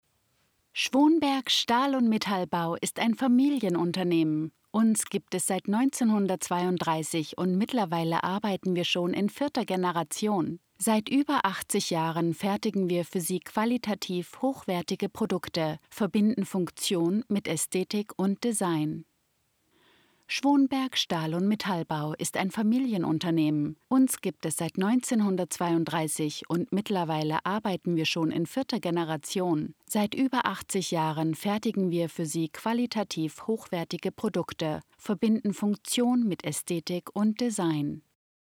Female
English (Neutral - Mid Trans Atlantic), German (Germany)
She records from her own professional studio and offers fast turnaround Her voice is international, smooth, confident, and professional, with a warm, authentic, and engaging millennial tone. A sophisticated trans-Atlantic sound ideal for global brands and storytelling.
Corporate
German Explainer Video